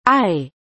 ●[ ei ] => [ai] (